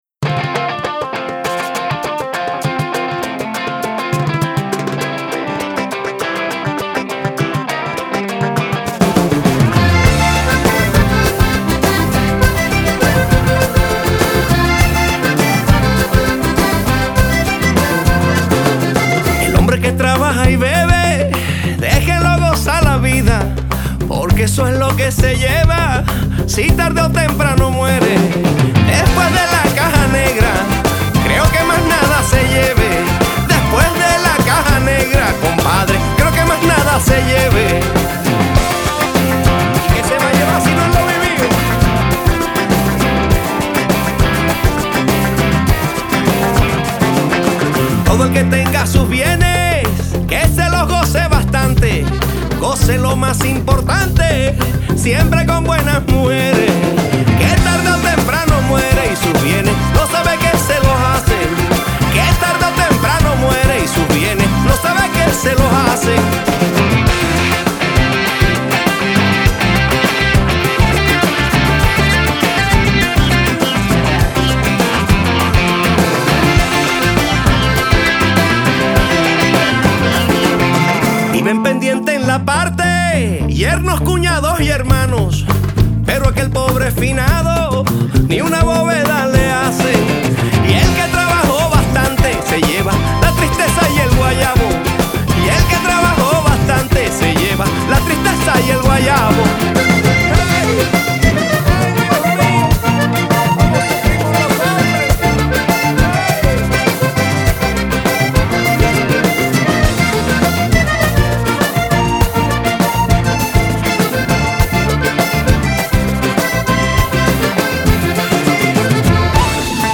Vallenato